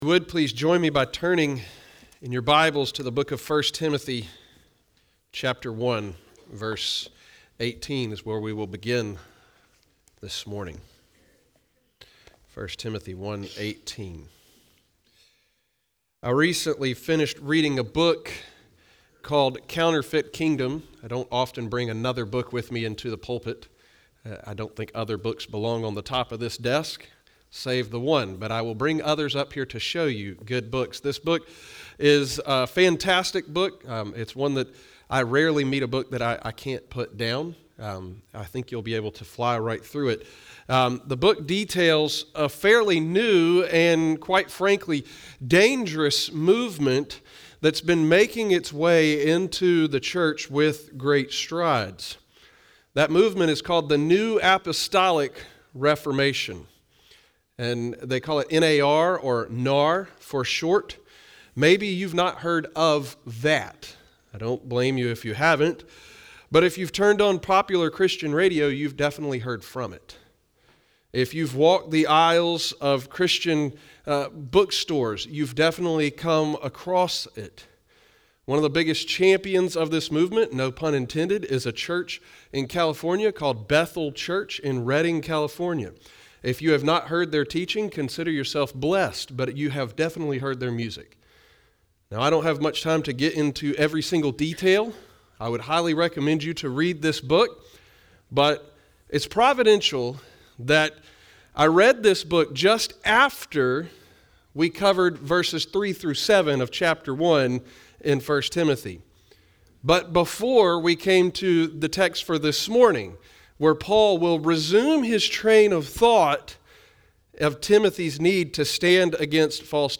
Visit Who We Are What We Believe Leadership Connect Children Students Adults Events Sermons Give Contact A Fight Worth Having February 5, 2023